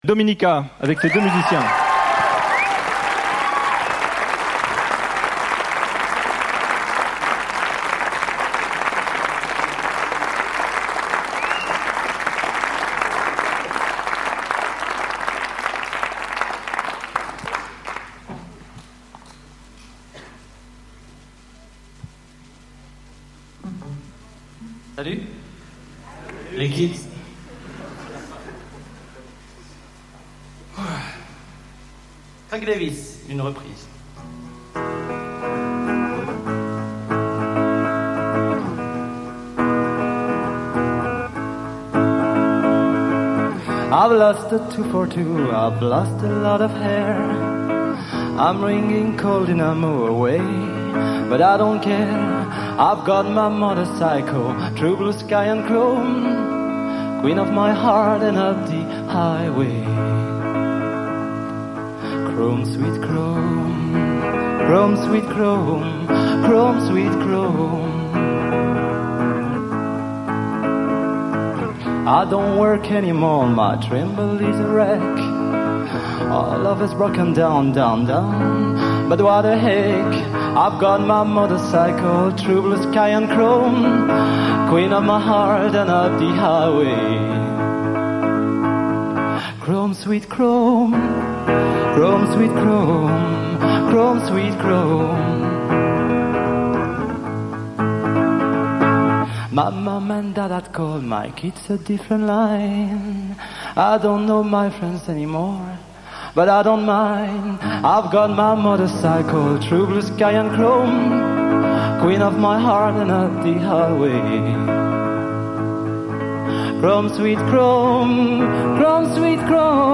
enregistrée le 04/02/1994  au Studio 105